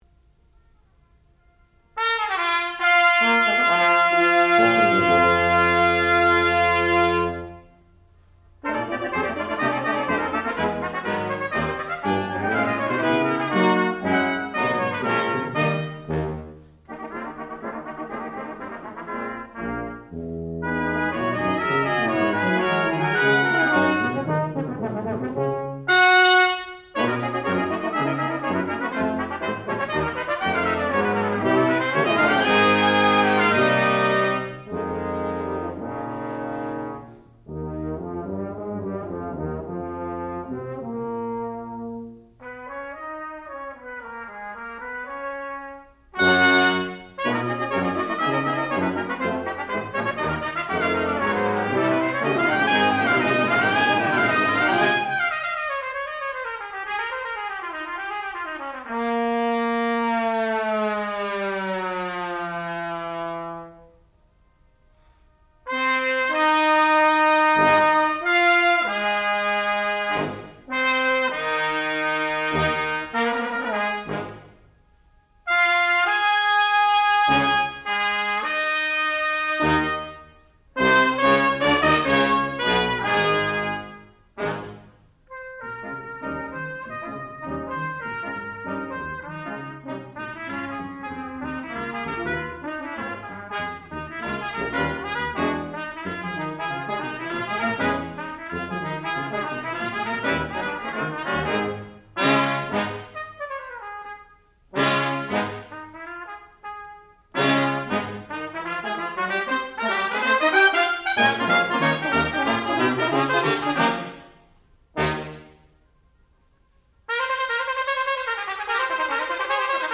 sound recording-musical
classical music
trumpet
trombone